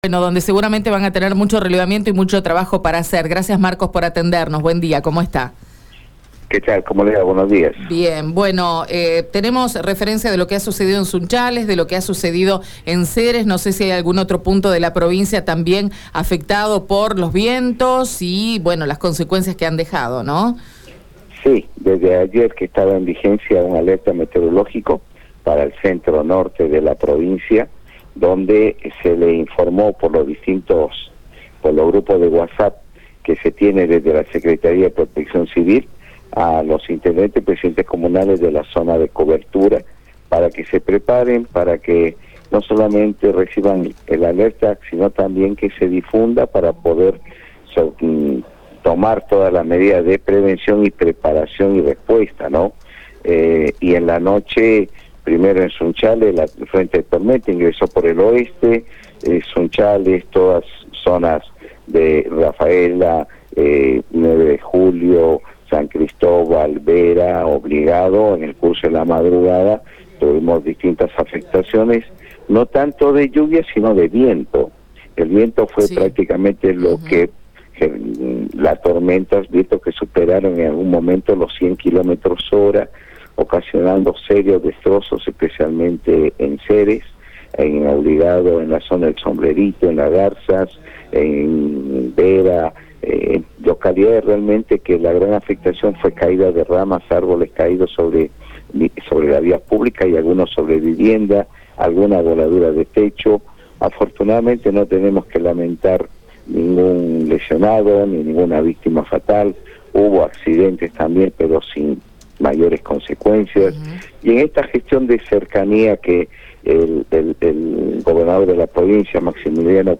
Radio EME dialogó con el titular de Protección Civil de la provincia, Marcos Escajadillo: «Desde ayer estaba en vigencia el alerta meteorológico para el centro-norte de la provincia. Se informó a través de grupos de whatsapp que tiene la secretaria de Protección Civil a todos los intendentes y presidentes comunales de la zona de cobertura para que tomen todas las medidas de prevención, preparación y respuesta».
Escucha la palabra de Marcos Escajadillo en Radio EME: